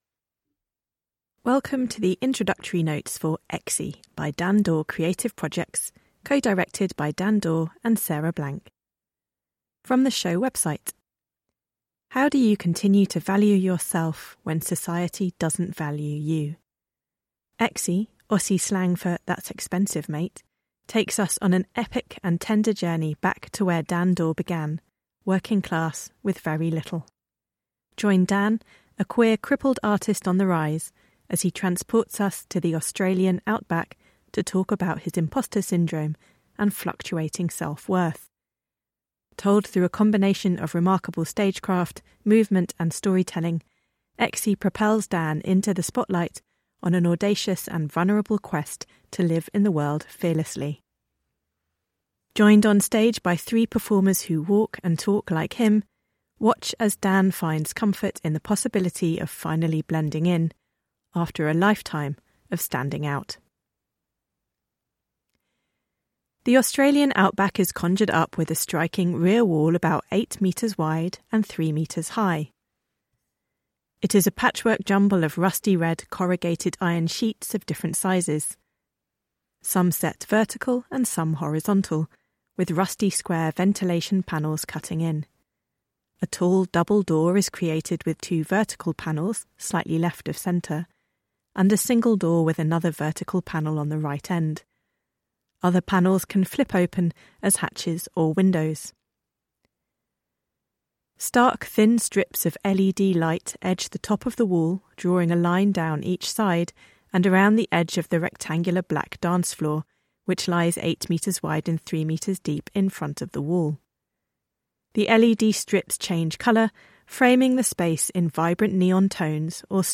Audio Described Introductory Notes (Word doc) Audio Described Introductory Notes (mp3) Content Sudden loud noises and music Flashing lights Blackouts Haze Pre-Show Information All performances will be Relaxed .
EXXY-intro-notes-1.mp3